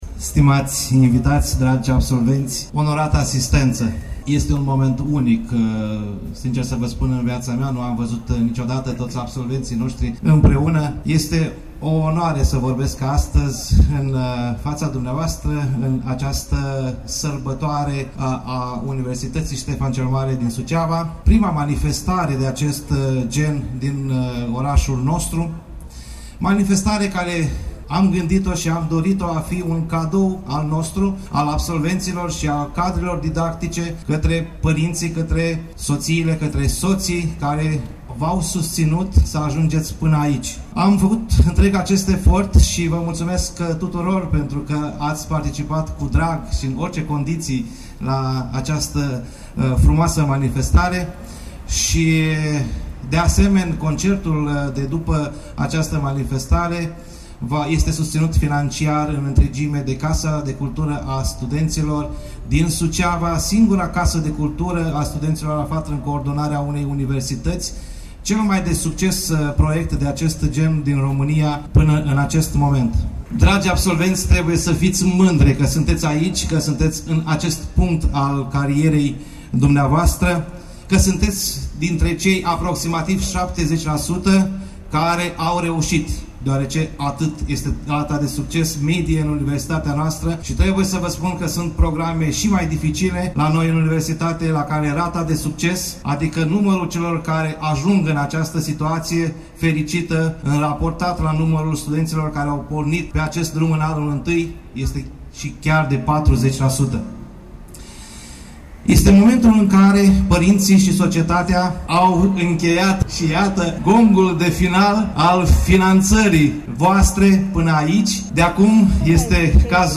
Absolvenții promoției 2017 au avut parte de cea mai frumoasă festivitate de absolvire, la care au fost prezenți profesori, părinți, prieteni și oficialități locale din Suceava. Rectorul Universității ,,Ștefan cel Mare” din Suceava,  prof. univ. dr. ing. Valentin Popa le-a transmis acestora numai gânduri bune, felicitări și i-a îndrumat să se îndrepte către o carieră de succes.
discurs-Rector-Popa.mp3